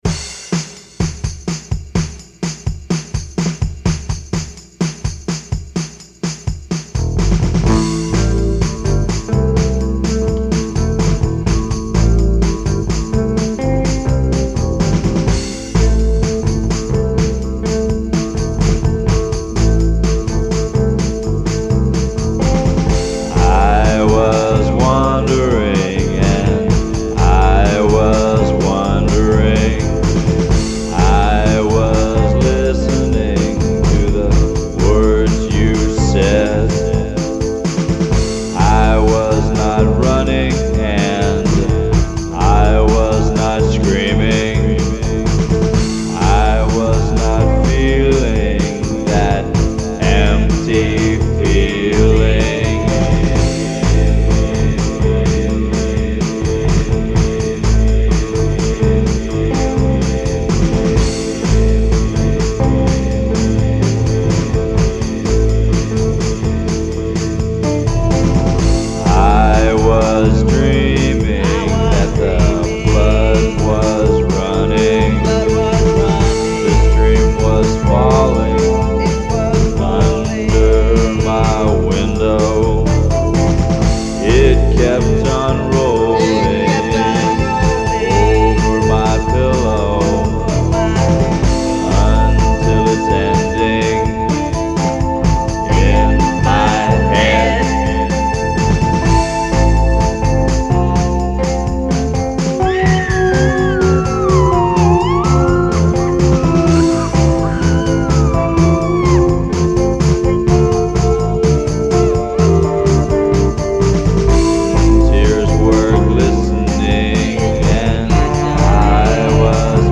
I added all the sound effects later.